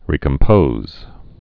(rēkəm-pōz)